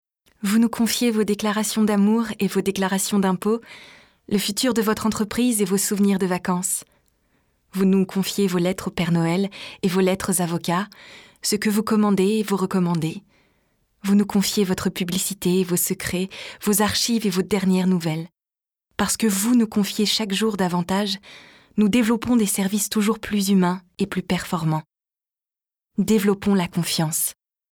EXTRAITS VOIX
PUBLICITES /SLOGAN /BANDE ANNONCE